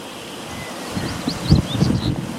Red-winged Tinamou (Rhynchotus rufescens)
Province / Department: San Luis
Location or protected area: Ruta Provincial 1
Condition: Wild
Certainty: Recorded vocal